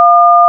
Les sons que vous allez traiter sont des signaux de codes DTMF (Dual Tone Multiple Frequency) utilisés pour distinguer par le son les touches numérotées d'un clavier téléphonique (DTMF sur wikipedia).
L'appuie sur une touche génère un son composé de deux fréquences, selon le tableau suivant :